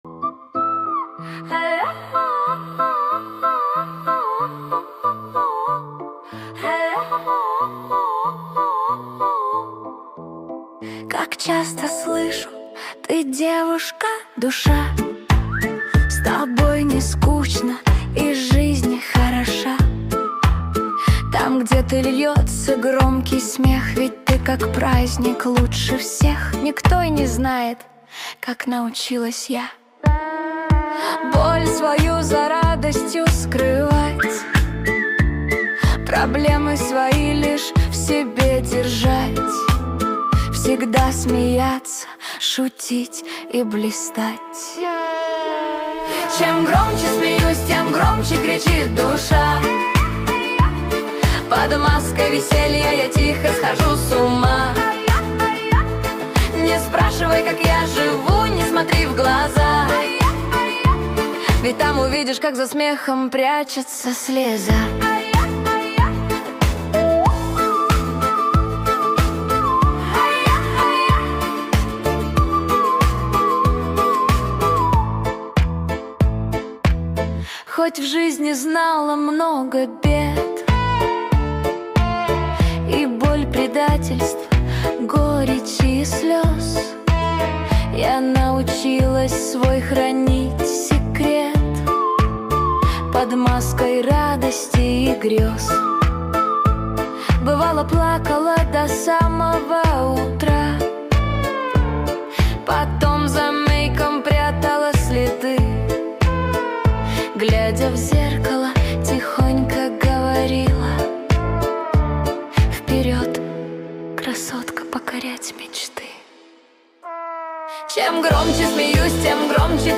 Качество: 217 kbps, stereo
Нейросеть Песни 2025